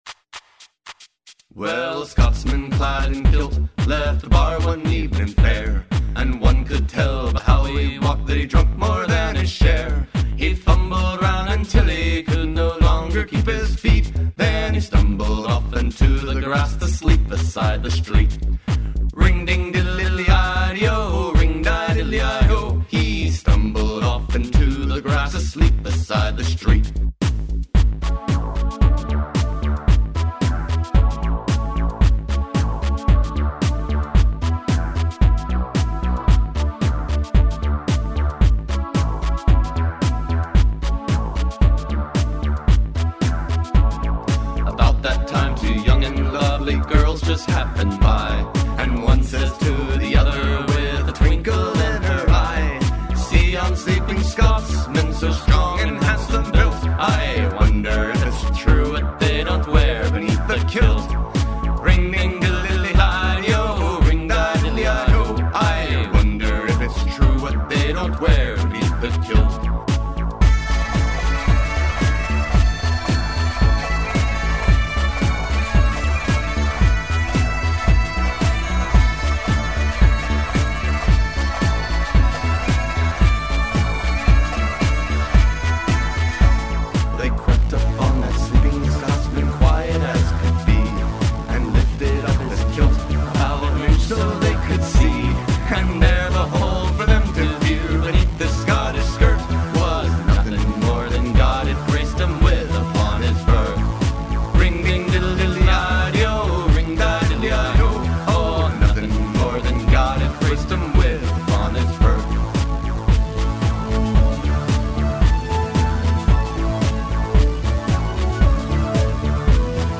dance remix